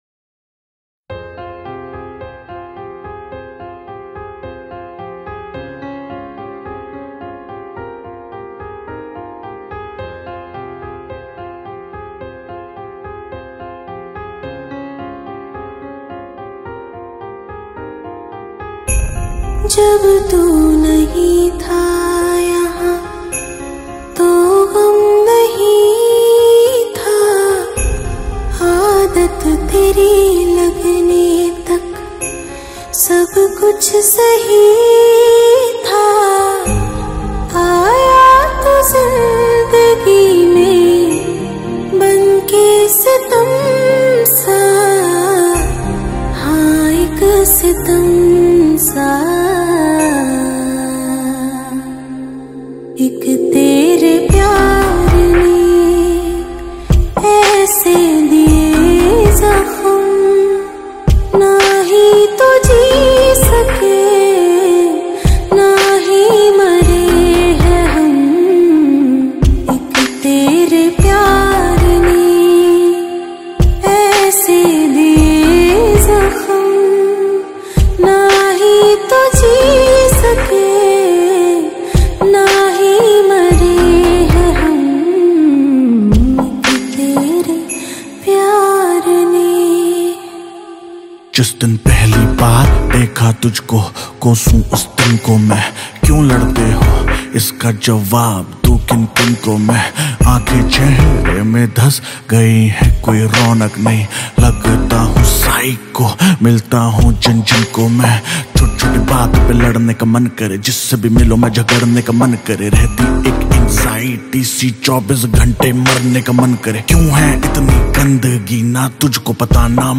2020 Pop Mp3 Songs